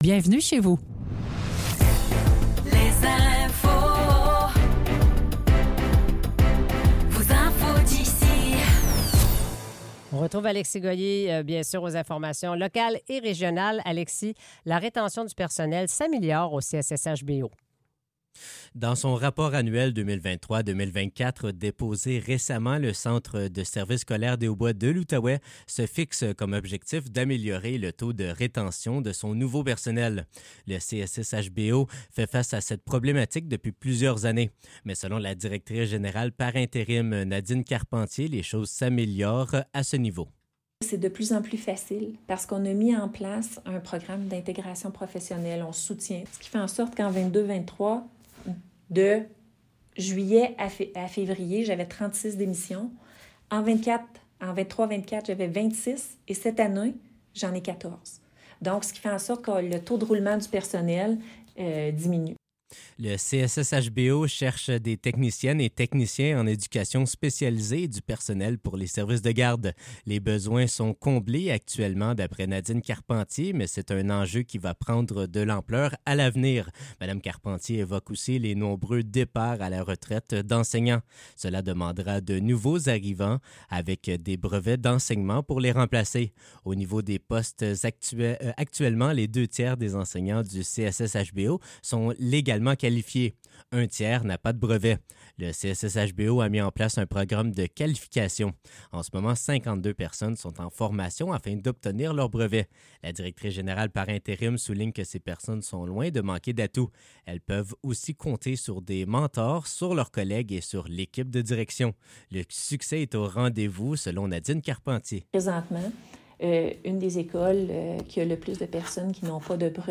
Nouvelles locales - 14 mars 2025 - 8 h